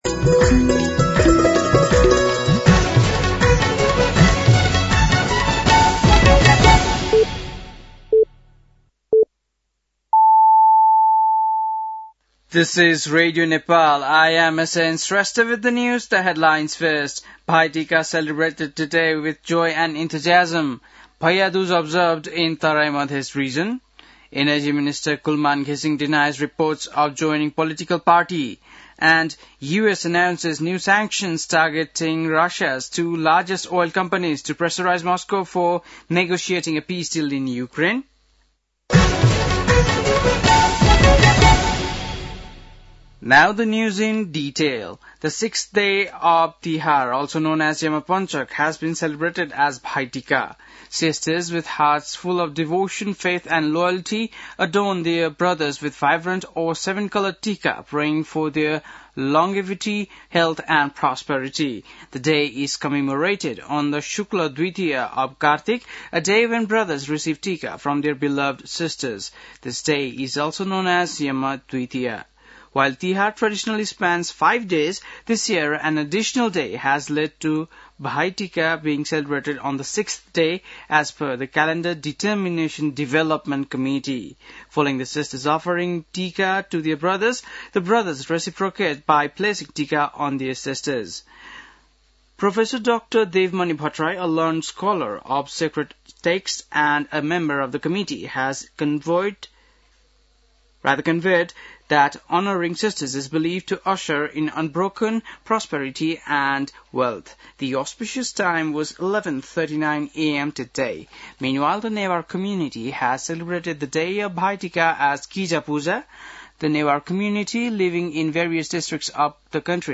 बेलुकी ८ बजेको अङ्ग्रेजी समाचार : ६ कार्तिक , २०८२
8-pm-english-news-7-6.mp3